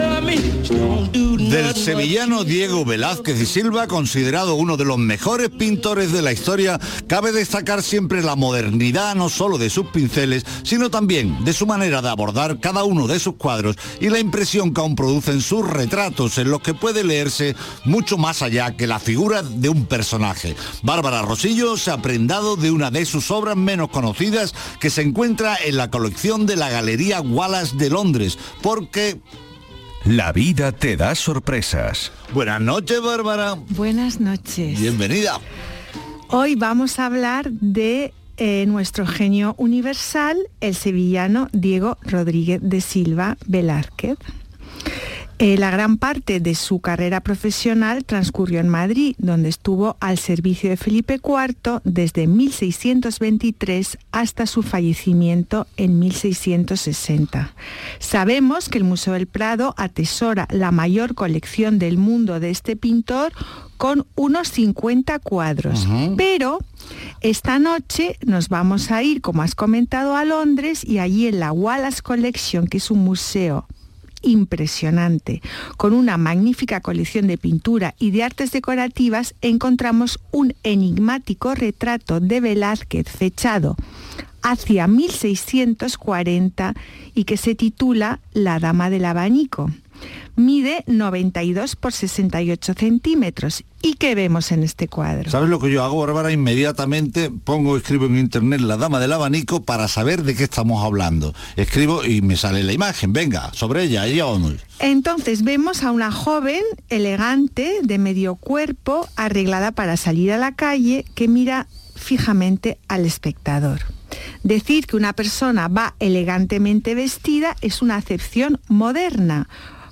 Art, Arte, españa, Historia, history, Indumentaria, radio